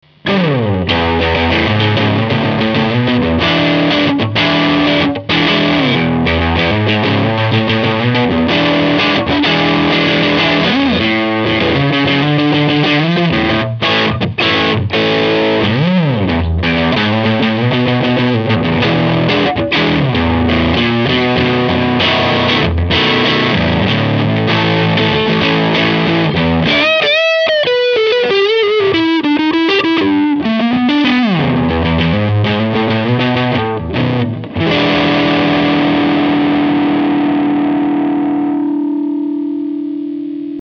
ыы, я овердрайв спешл собирал когда-то...
drive_full_neck.mp3